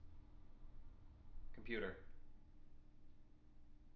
tng-computer-03.wav